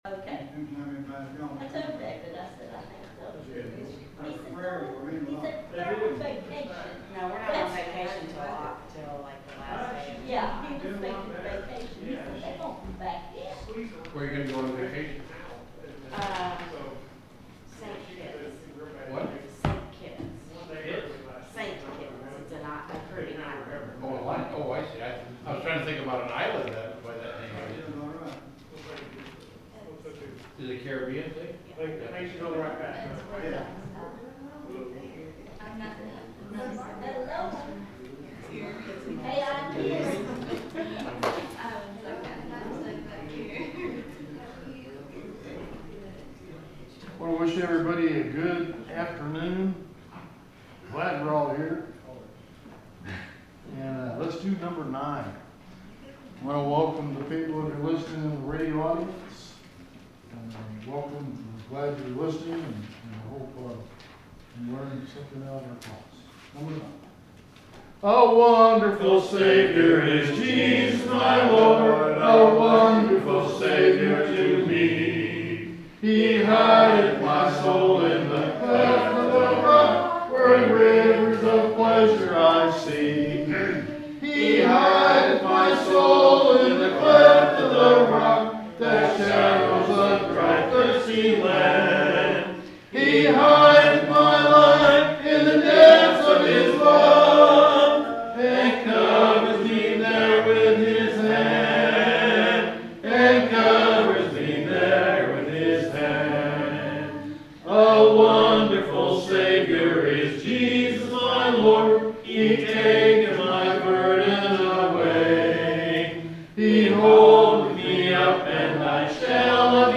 The sermon is from our live stream on 7/9/2025